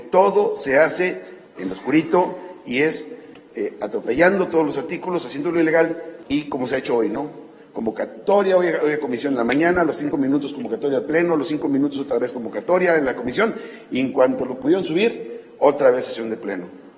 sesion-congreso-pleno.mp3